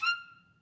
Clarinet
DCClar_stac_F5_v1_rr2_sum.wav